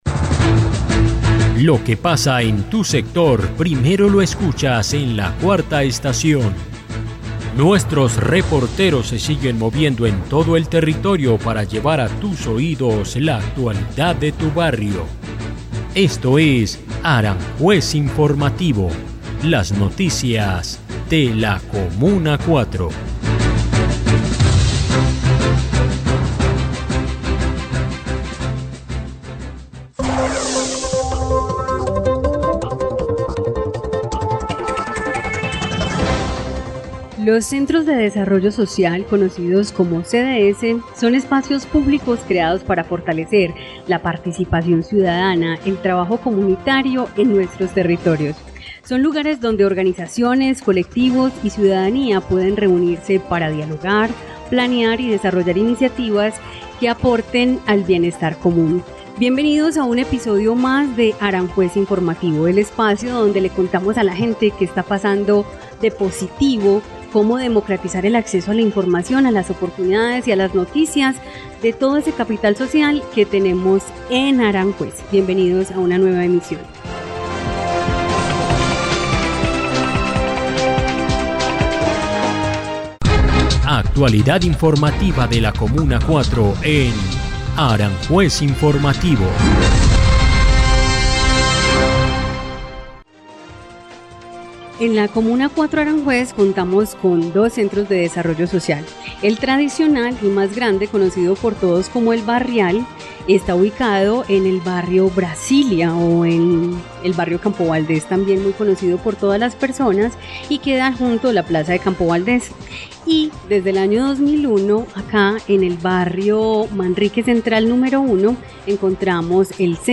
En este nuevo capítulo de Aranjuez Informativo, conversamos con ella, donde nos cuenta diferentes aspectos de su llegada al cargo, los retos que asume y las proyecciones del proceso.